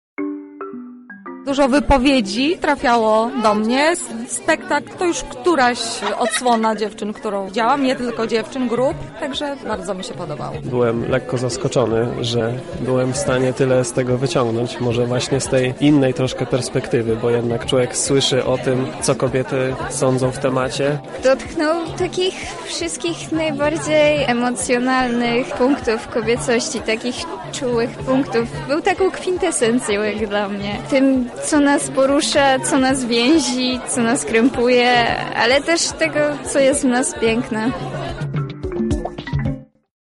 Na temat występów wypowiedzieli się uczestnicy: